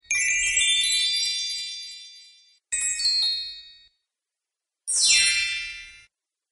Christmas Bells Sparkle